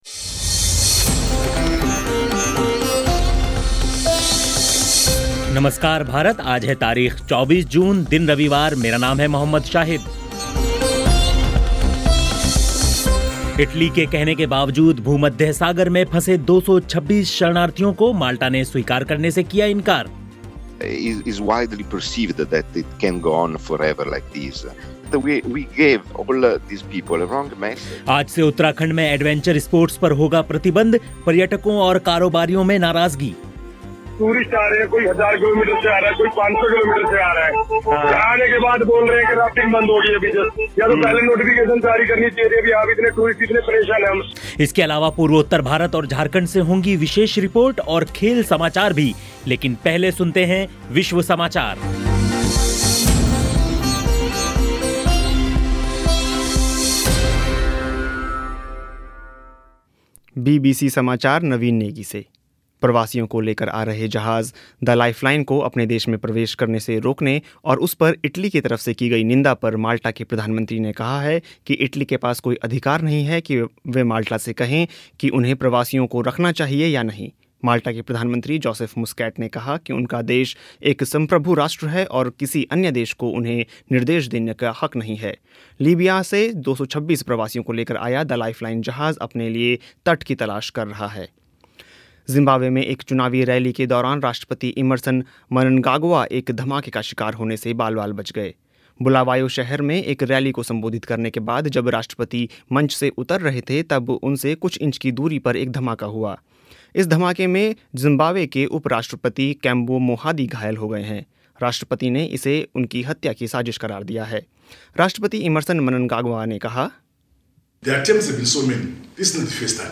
इसके अलावा पूर्वोत्तर भारत और झारखंड से होंगी विशेष रिपोर्ट और खेल समाचार भी, लेकिन पहले सुनते हैं विश्व समाचार.